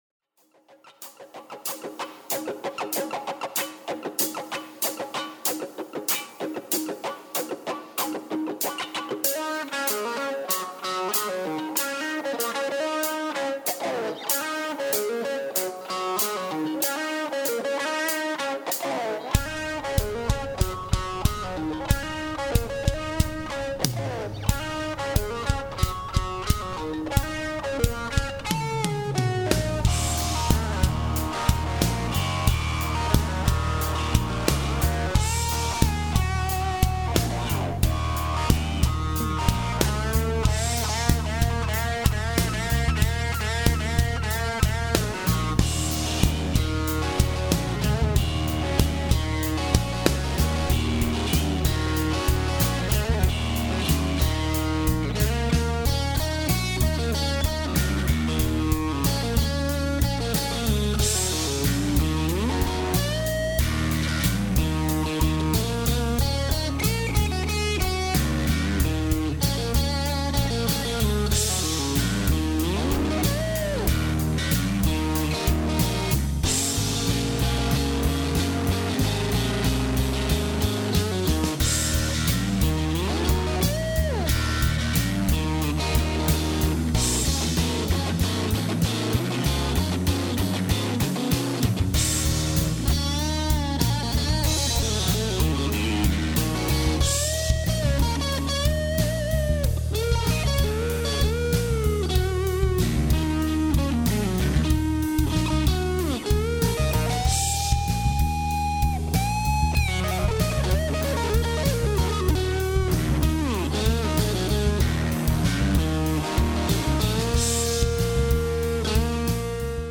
lesson sample